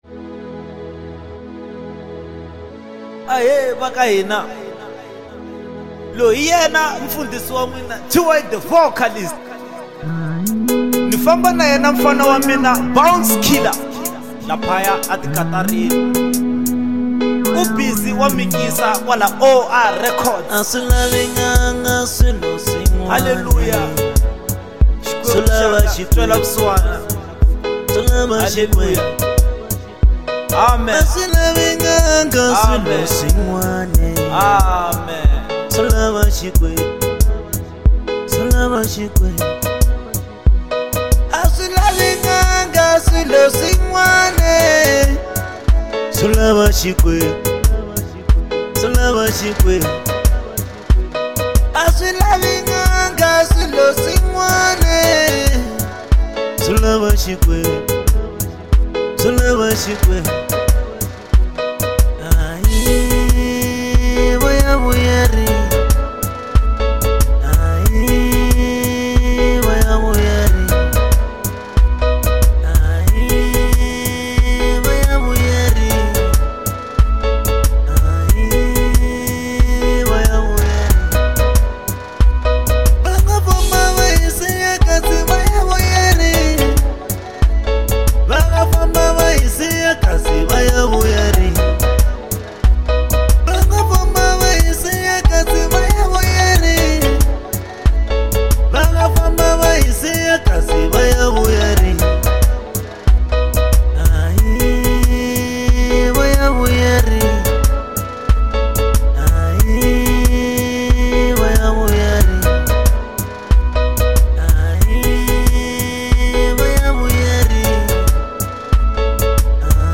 05:17 Genre : Marrabenta Size